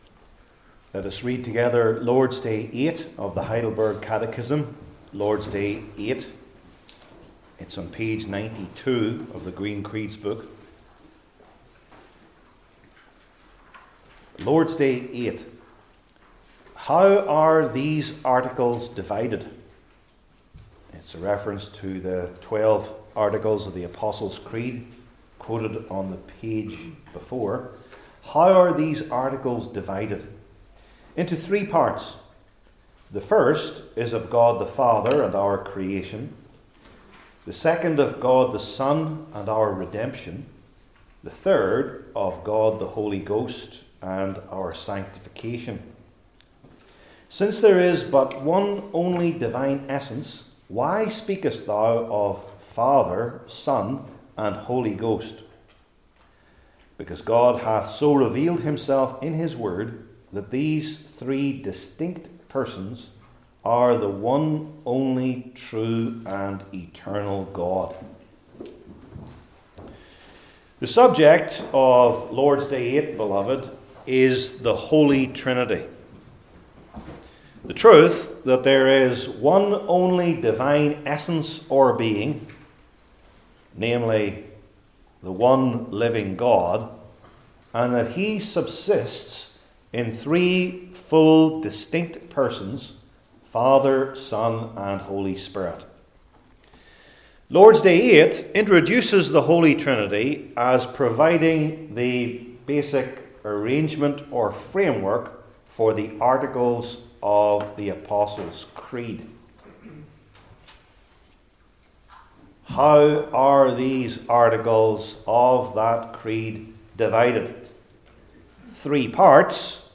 Heidelberg Catechism Sermons I. The Meaning II.